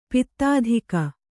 ♪ pittādhika